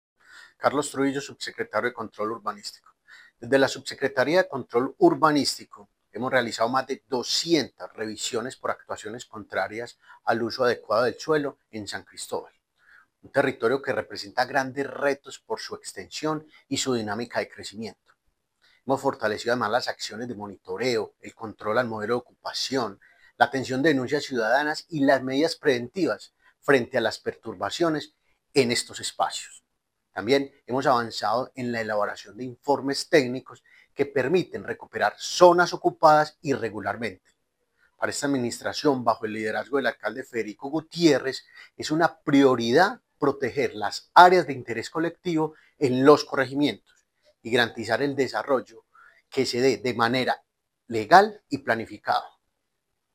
Declaraciones del subsecretario de Control Urbanístico, Carlos Trujillo Vergara
Declaraciones-del-subsecretario-de-Control-Urbanistico-Carlos-Trujillo-Vergara.mp3